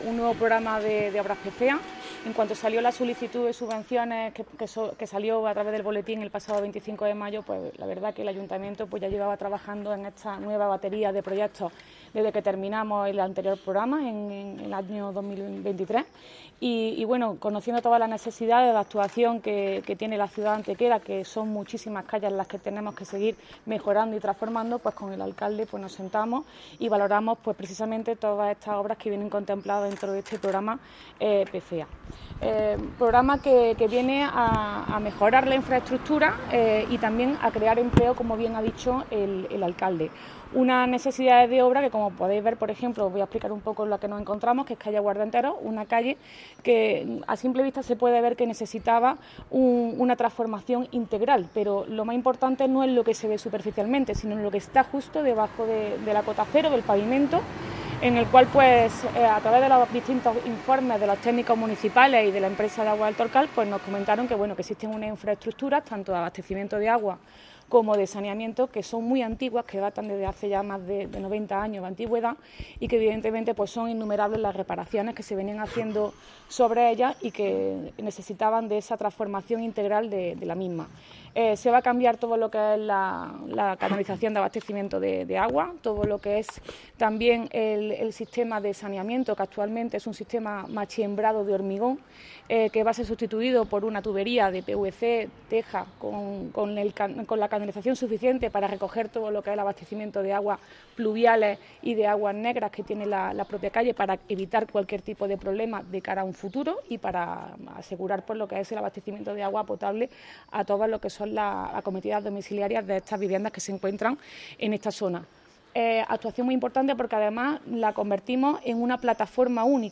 El alcalde de Antequera, Manolo Barón, y la teniente de alcalde delegada de Obras y Mantenimiento, Teresa Molina, han presentado en rueda de prensa los proyectos que formarán parte de las obras PFEA en su anualidad 2024/2025, con la pretensión de que puedan iniciarse, tras las preceptivas licitaciones aparejadas, en el último trimestre del año.
Cortes de voz